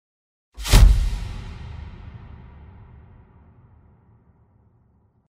Звуки Impact
Слушайте онлайн и скачивайте бесплатно качественные ударные эффекты, которые идеально подойдут для монтажа видео, создания игр, рекламных роликов и постов в соцсетях.